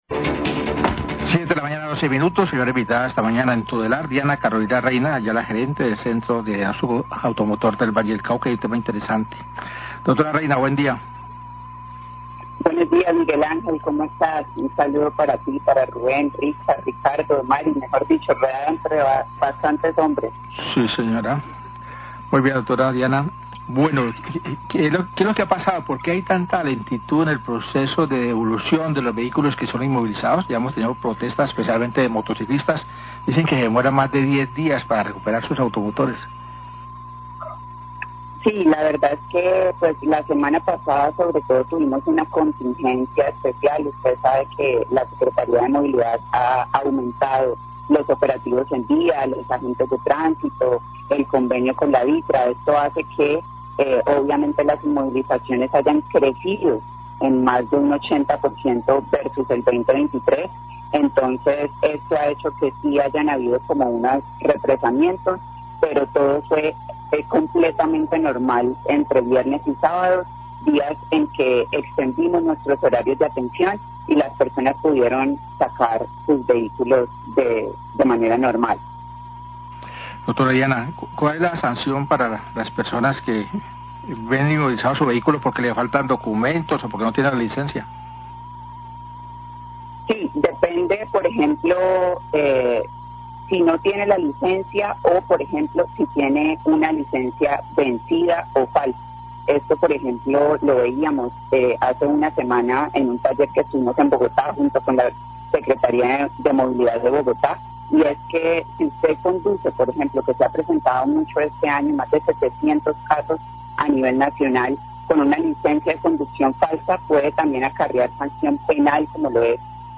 Radio
entrevistas